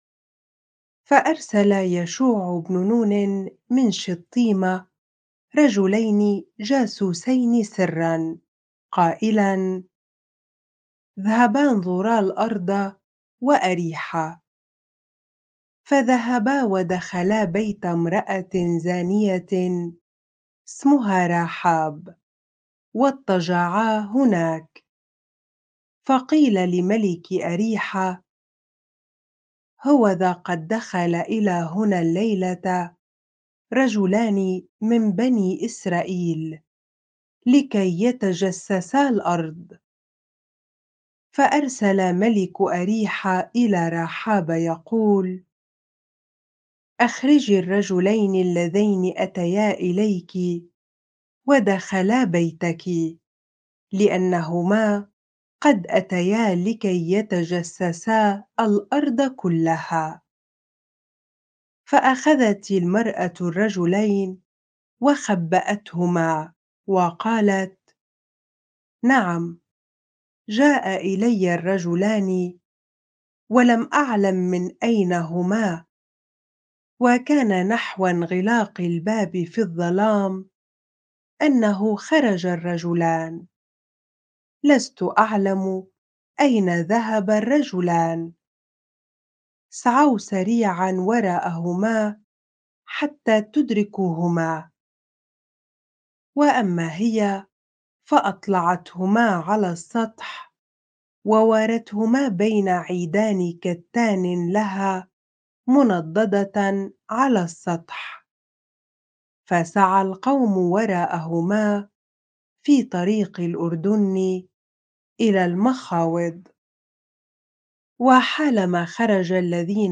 bible-reading-joshua 2 ar